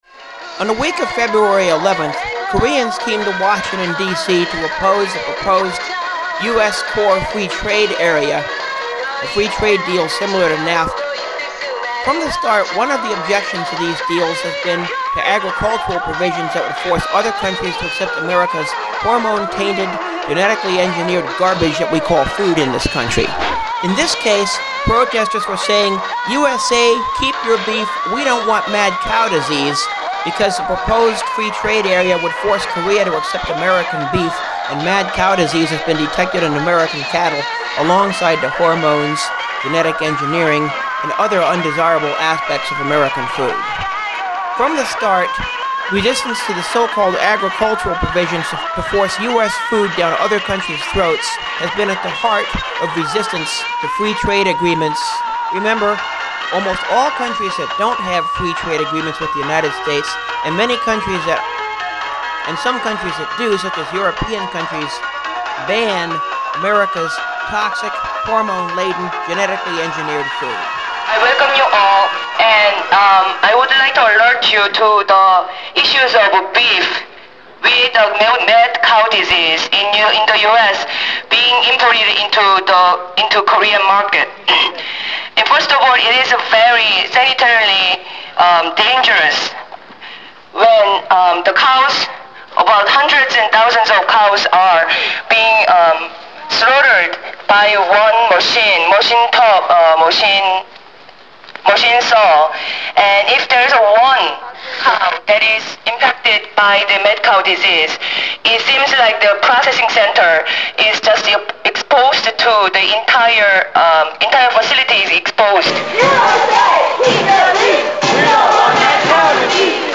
Korean protestors in Wash DC march against US-Kor free trade area, US mad cow beef
Several times, protestors chanted "USA, keep your beef-we don't want mad cow disease!"
At several points in the march, chants of "Be Agressive!" preceded mass charges of about a block in length, and at other points everyone lay down in the streets symbolizing the deaths of Korean farmers due to previous trade deals.